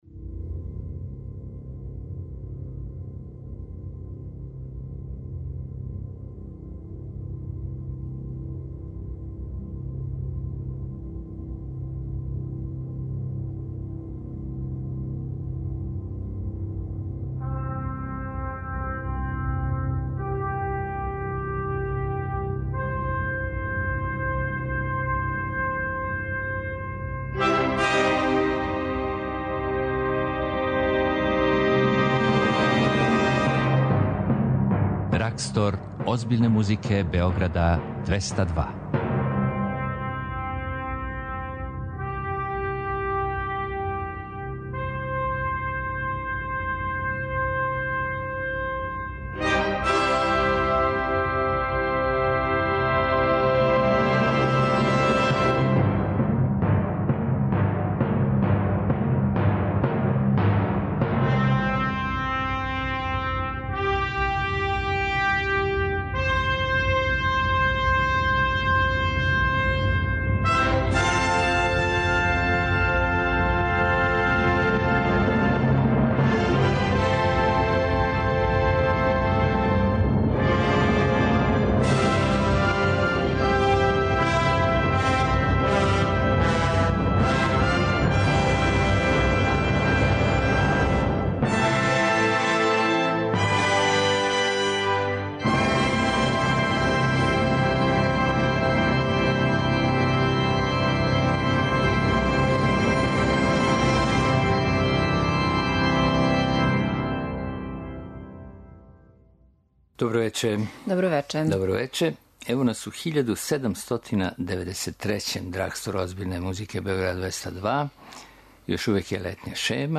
Култна емисија Београда 202 која промовише класичну музику.